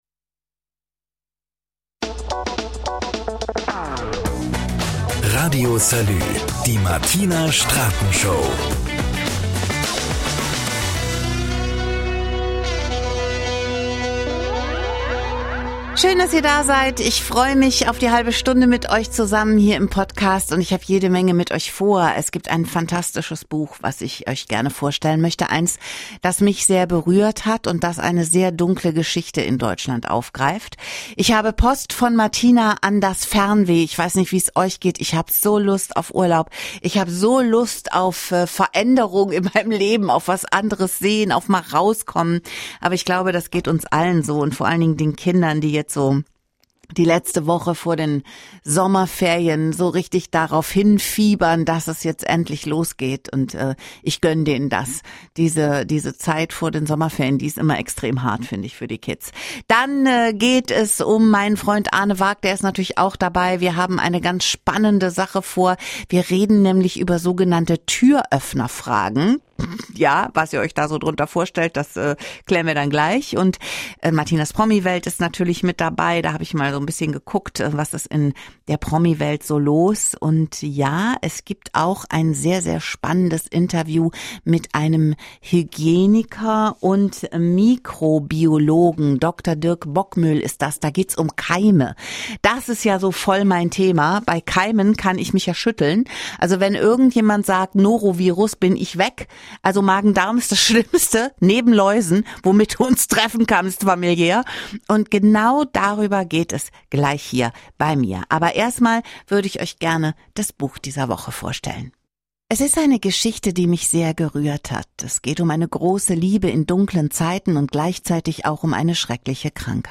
Er ist Mikrobiologe, kennt sich aus in dem Thema und er führt uns wunderbar anschaulich in das Reich unserer unsichtbaren Untermieter.